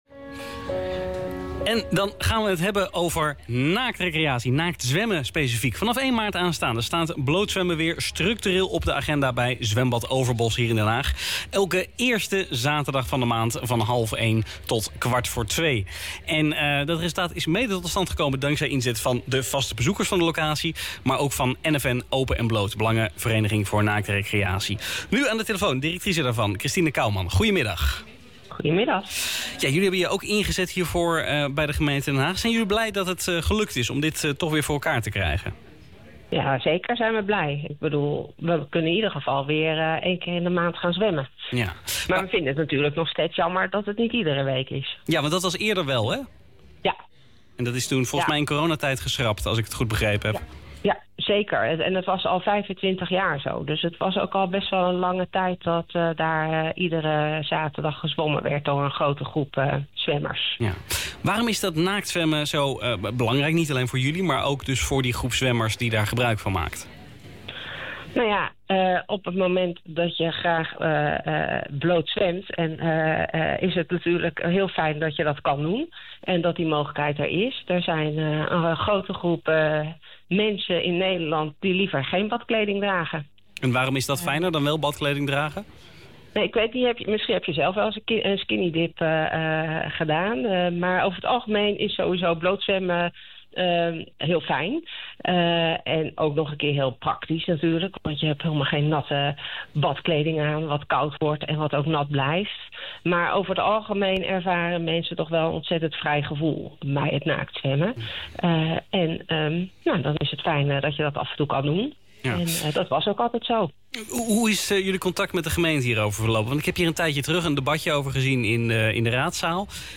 • Radio-interview: Den Haag FM n.a.v. berichtgeving naaktzwemmen Den Haag (